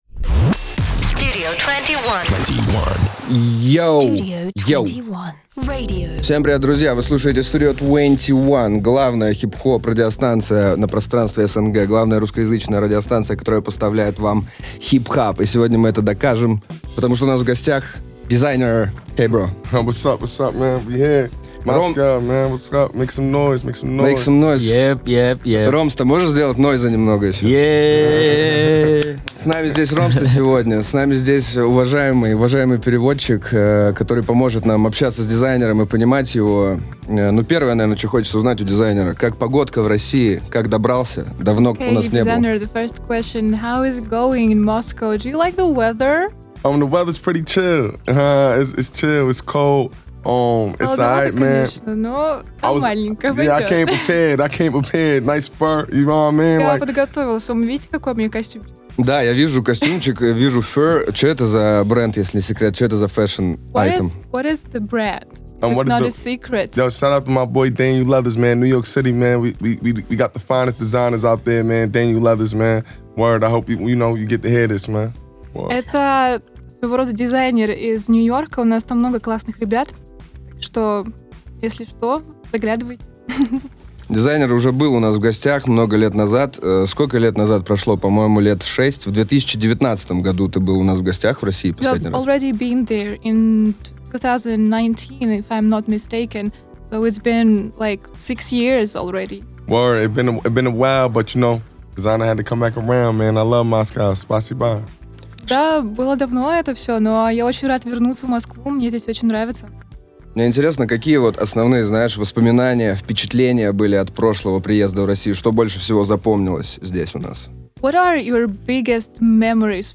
Desiigner – в эфире STUDIO 21.